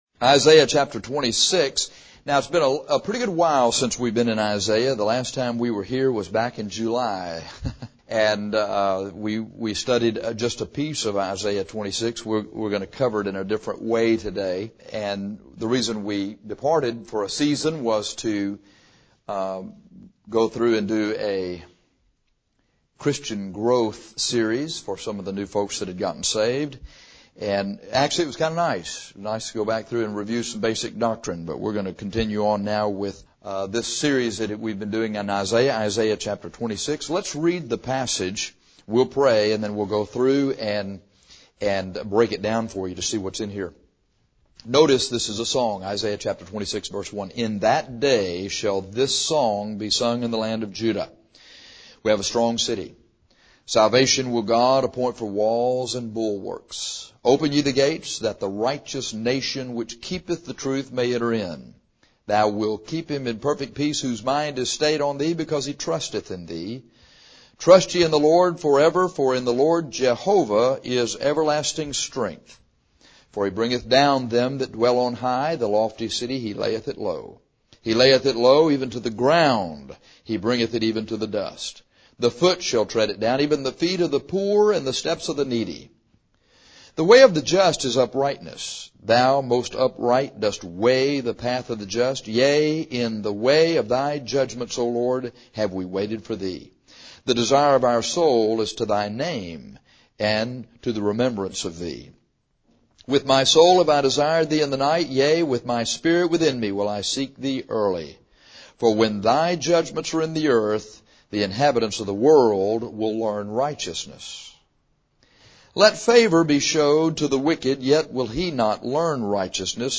This lesson is about Israel’s Song in the Millennium.